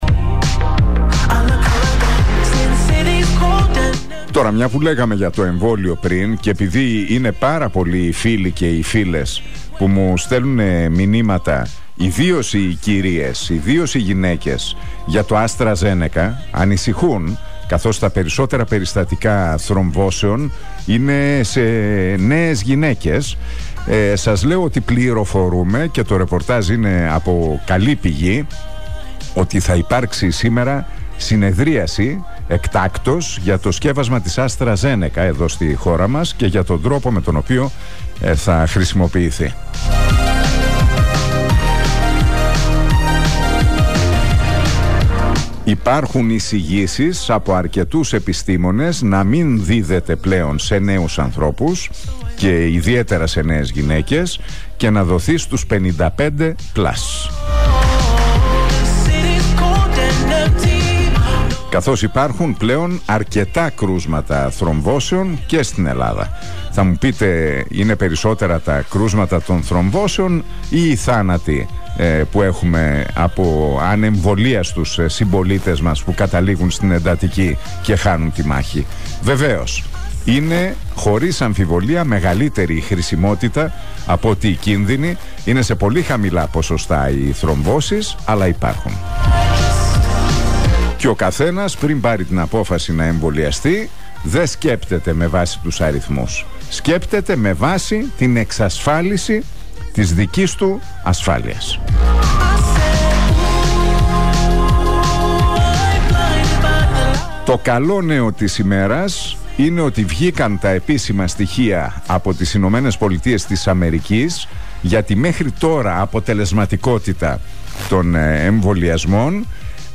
Σύμφωνα με πληροφορίες που μετέδωσε ο Νίκος Χατζηνικολάου στον RealFm 97,8, σήμερα αναμένεται να υπάρξει έκτακτη συνεδρίαση για το συγκεκριμένο ζήτημα και εκτιμάται ότι θα υπάρξει εισήγηση από τους γιατρούς να μην χορηγείται στους νέους αλλά σε όσους είναι άνω των 55 ετών.